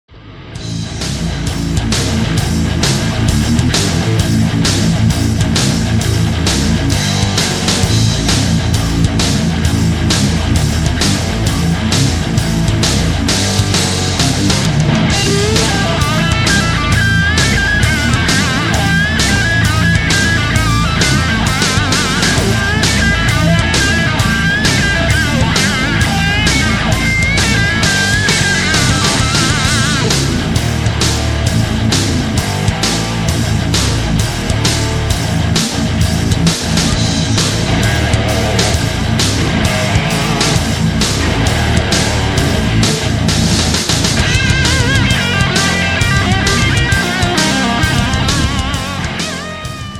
Instrumental Ringtones